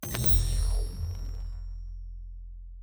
Shield Device 2 Start.wav